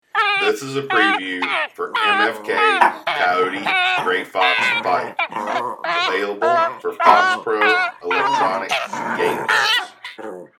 MFK Coyote Gray Fox Fight – 16 bit
The Big Difference- Our one-of-a-kind live coyote library naturally recorded at extremely close